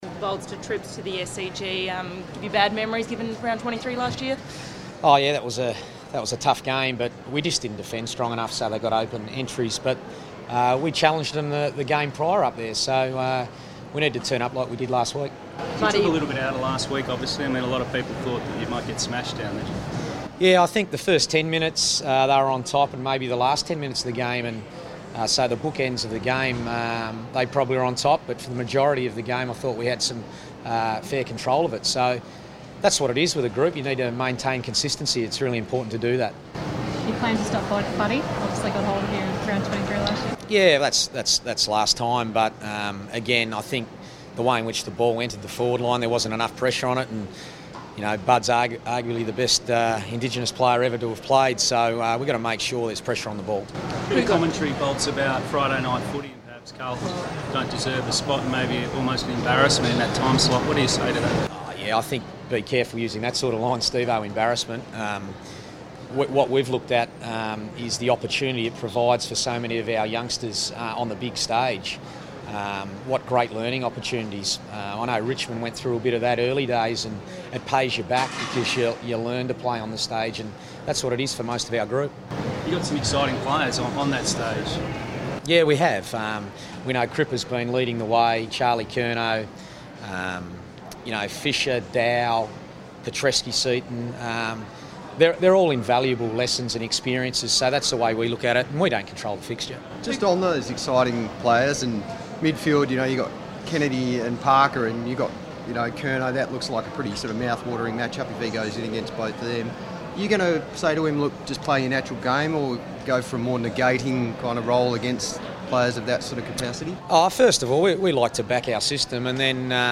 Brendon Bolton press conference | May 31
Carlton coach Brendon Bolton fronts the media at Melbourne Airport ahead of the Blues' trip to Sydney to face the Swans.